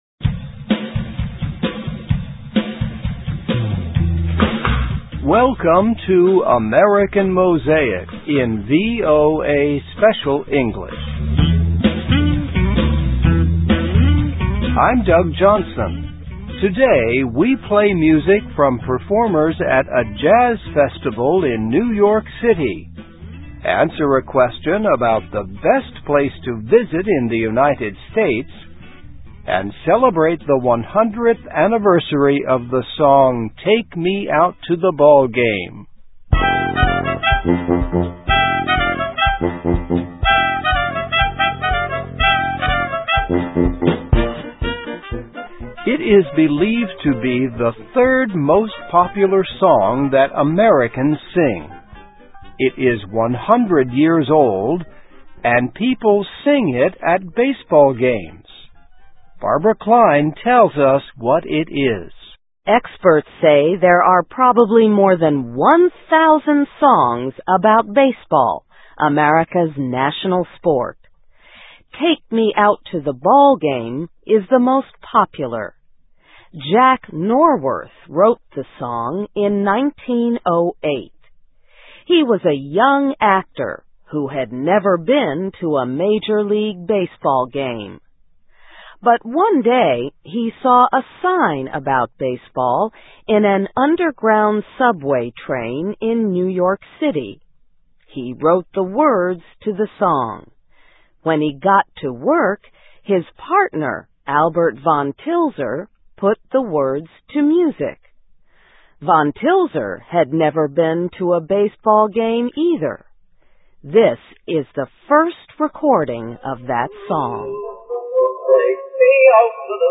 Today we play music from performers at a jazz festival in New York City …